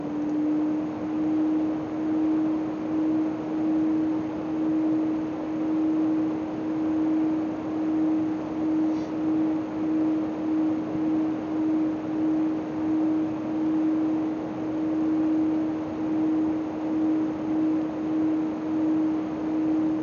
Beschreibe dein Problem: Von meinem GPU Lüfter nehme ich mittlerweile ein "unrundes" Geräusch wahr. Als ob der Lüfter "leiern"/wackeln würde oder so. Ein Rattern ist das jetzt aber auch nicht.
(zur Info: aufgenommen über Headset Mikrofon bei einem Abstand von ca. 1 bis 2 cm.) Das erste wäre der Start bzw. Deaktivierung von Zero Fan Mode und bei einer (mindest) Lüftergeschwindigkeit von 35% RPM / ~1300 RPM.